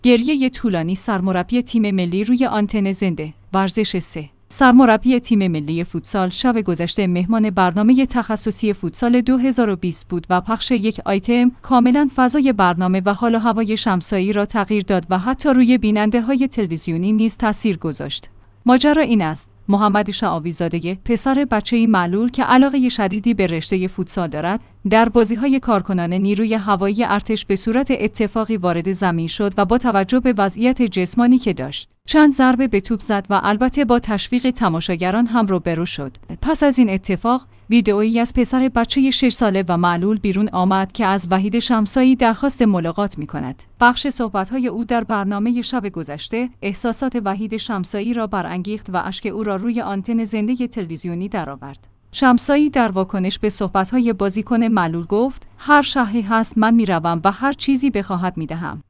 گریه طولانی سرمربی تیم ملی روی آنتن زنده